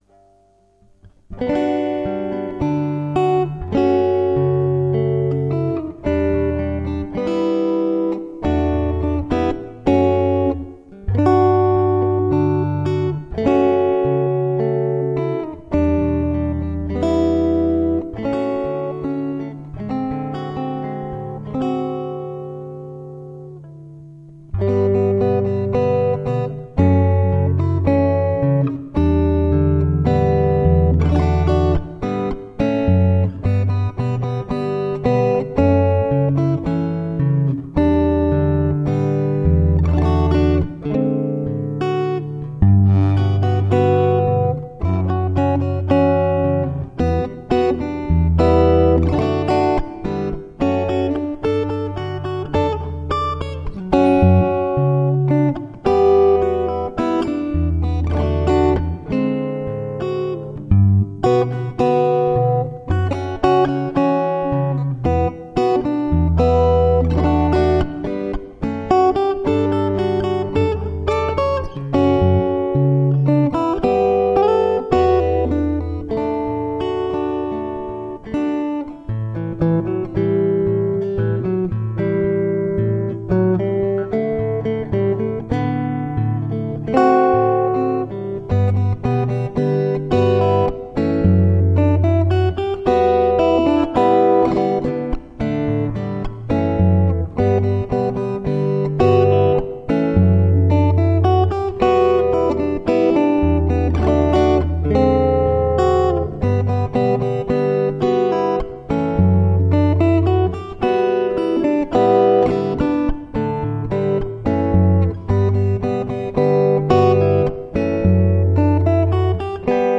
ミスった箇所もありますが、中居くん同様、何度やってもそれほど変化が無いので、あきらめてイッパツ取りにしました。